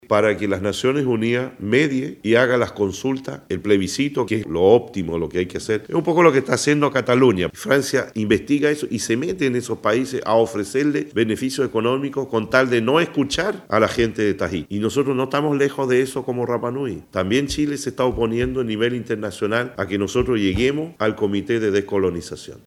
“También Chile se está oponiendo a nivel internacional a que nosotros lleguemos al Comité de Descolonización”, señaló Edmunds Paoa en conversación con Radio Bío Bío.